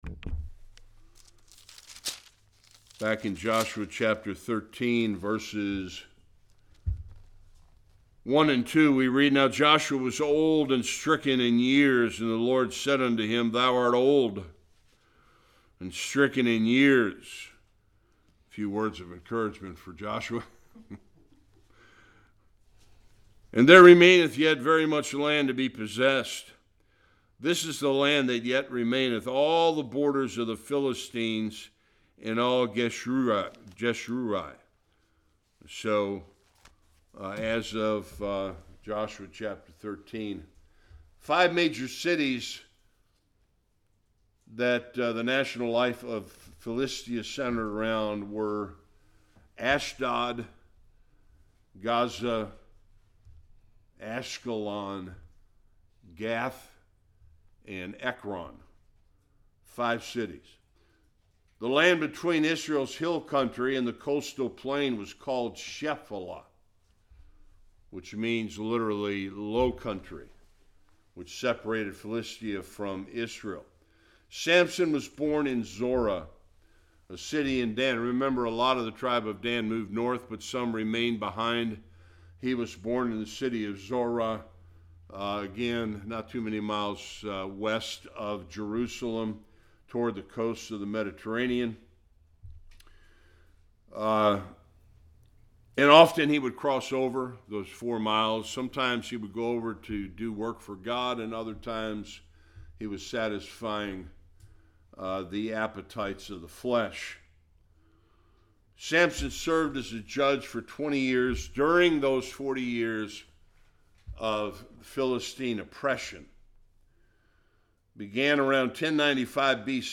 1-20 Service Type: Sunday School Samson was a man of faith according to Hebrews 11